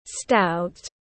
To khỏe tiếng anh gọi là stout, phiên âm tiếng anh đọc là /staʊt/ .